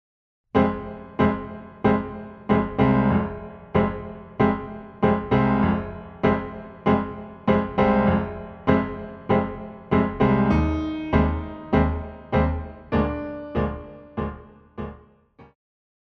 古典,流行
钢琴
演奏曲
世界音乐
仅伴奏
没有主奏
没有节拍器